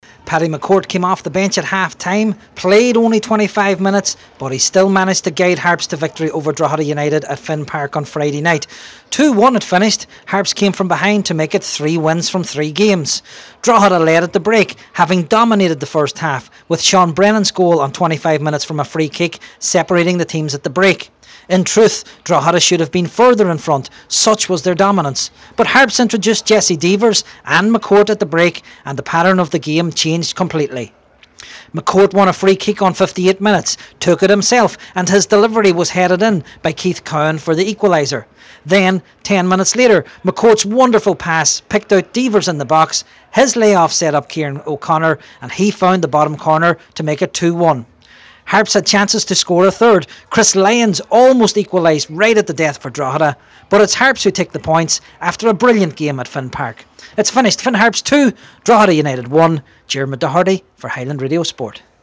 Harps beat Drogheda at home – FT Report